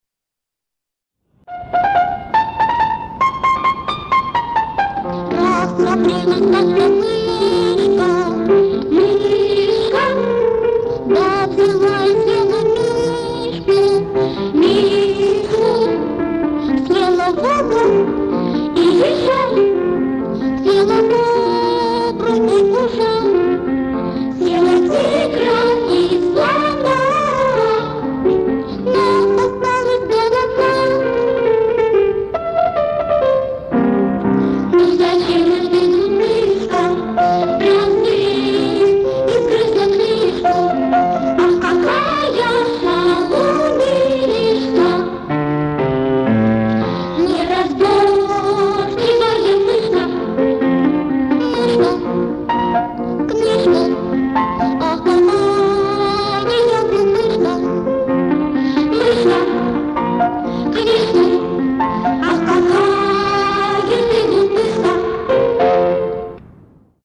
вокал, гитара
Детская песенка